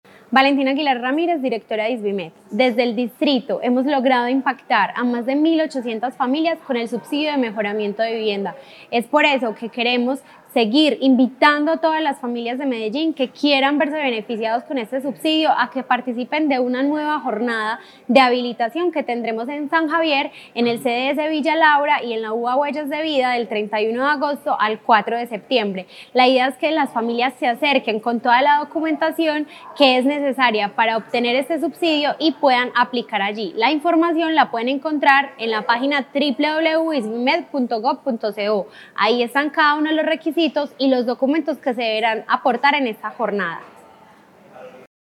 Declaraciones de la directora de Isvimed-, Valentina Aguilar Ramírez
Declaraciones-de-la-directora-de-Isvimed-Valentina-Aguilar-Ramirez-1.mp3